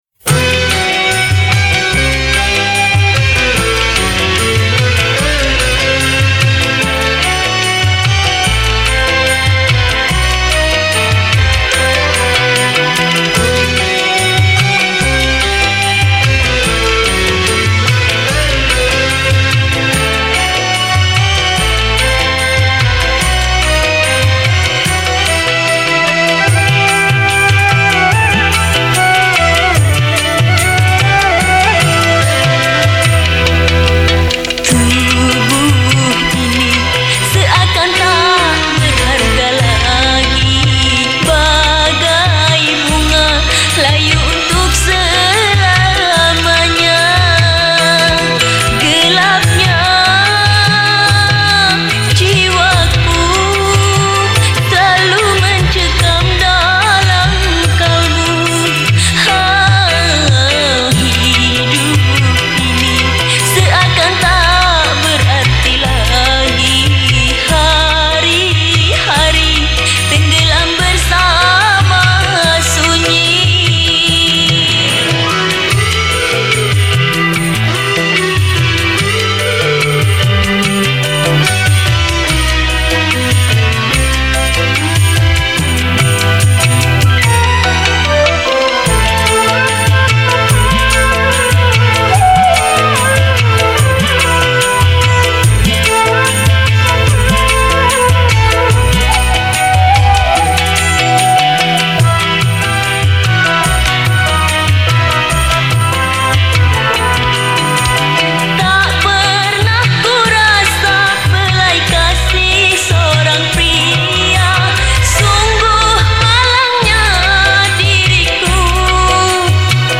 Instrumen                                     : Vokal